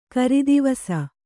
♪ karidivasa